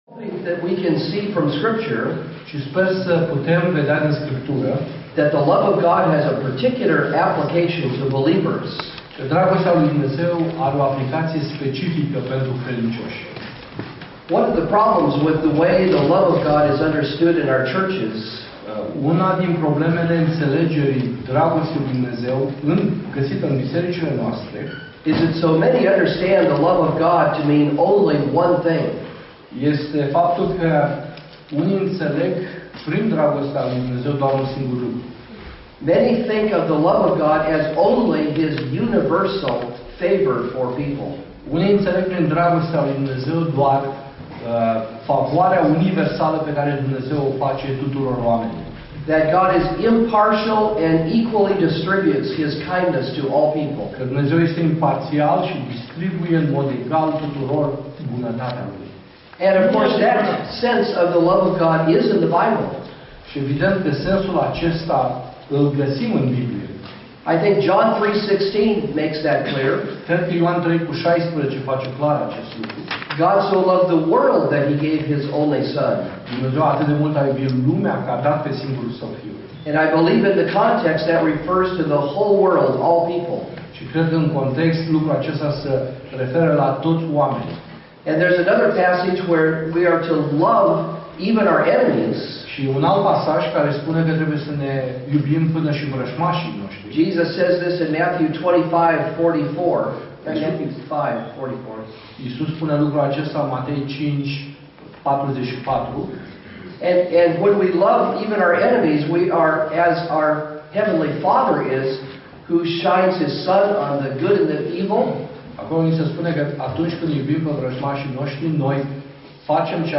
Predici Complete